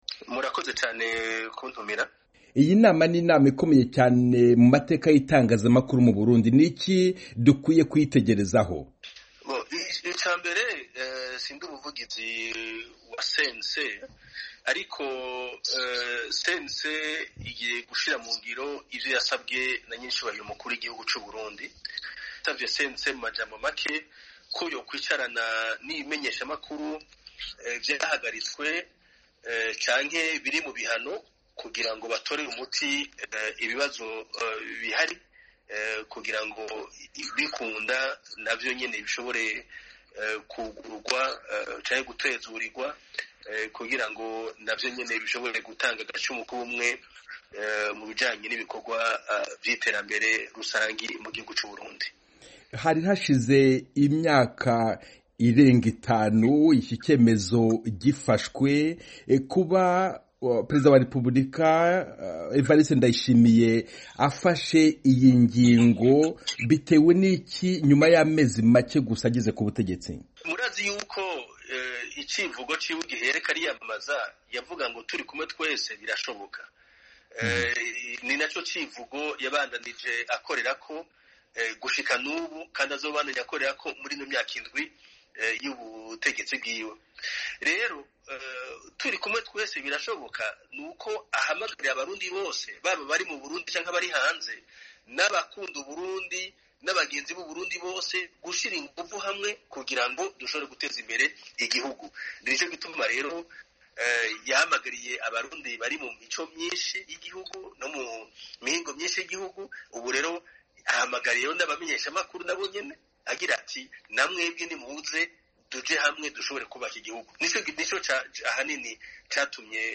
Ikiganiro na Willy Nyamitwe ku Nama ya CNC n'Ibinyamakuru Vyugawe